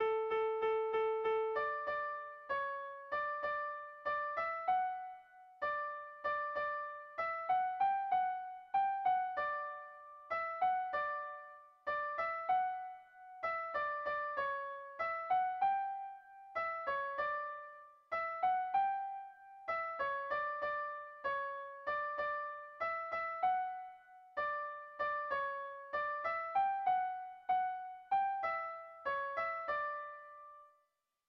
Bertso melodies - View details   To know more about this section
Kontakizunezkoa
Hamarreko txikia (hg) / Bost puntuko txikia (ip)
ABD1D2B2